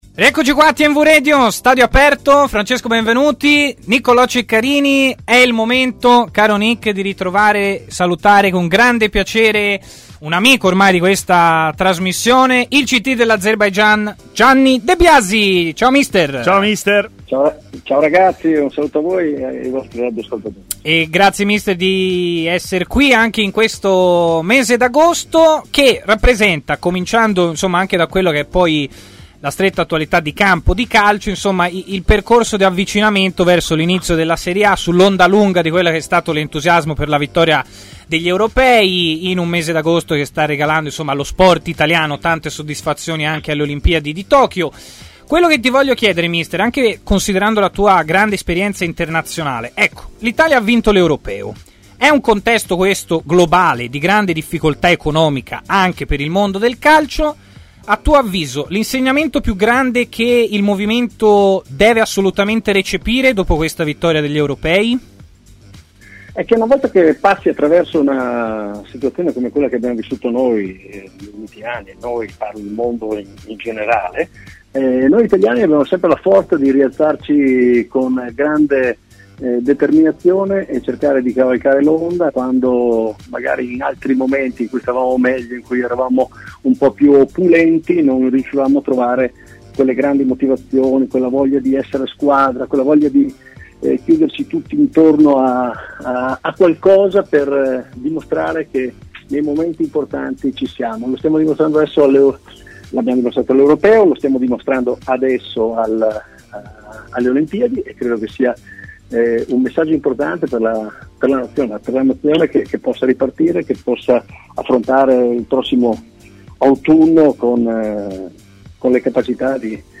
parlato a Stadio Aperto, trasmissione di TMW Radio